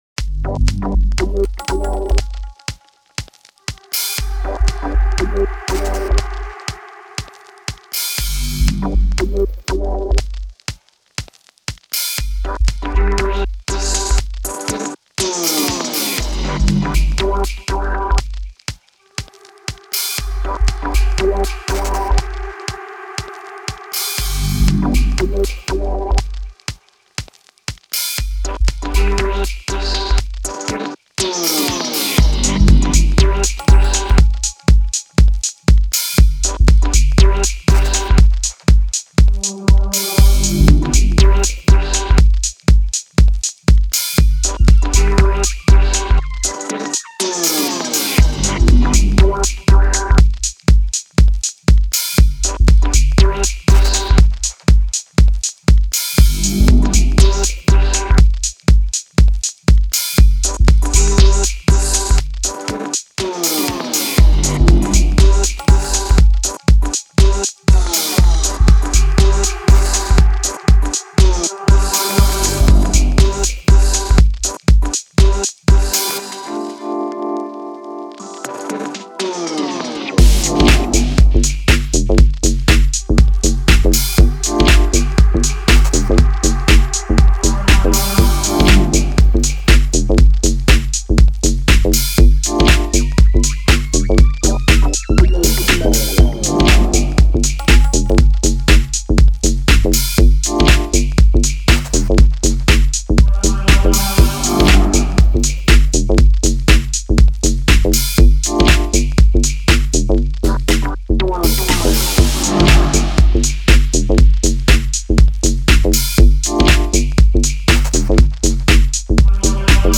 Жанр:Deep House